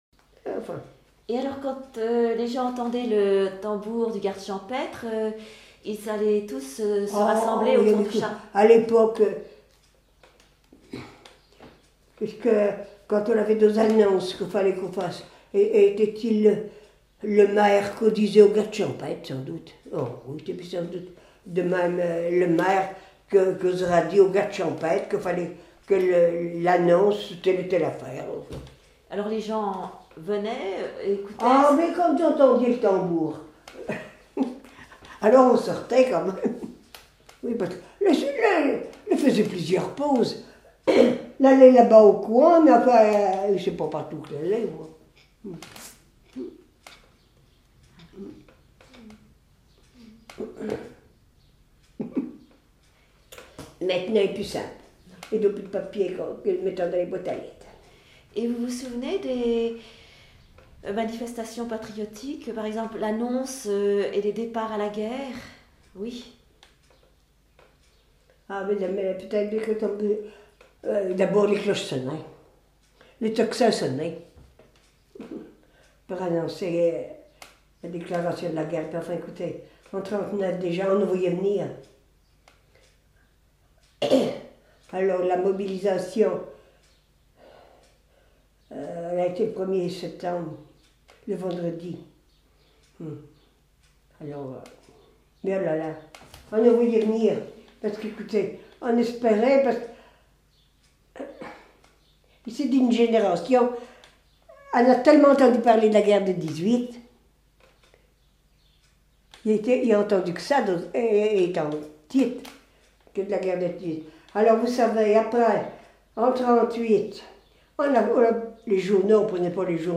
Témoignage sur la vie de l'interviewé(e)
Catégorie Témoignage